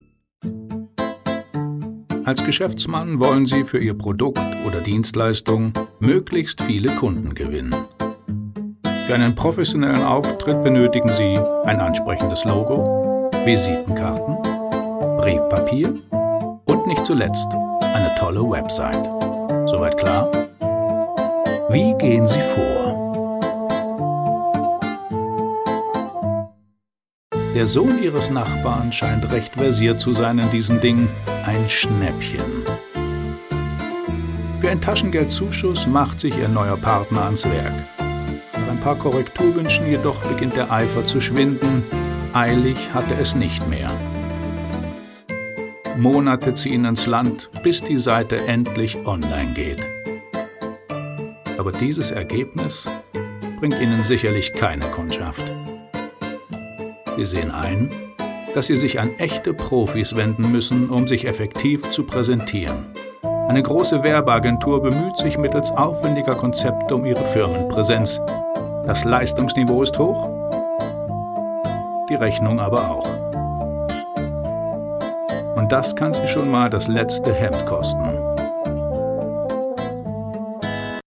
Stimmproben
PrimaLine-ImageFilm(1).mp3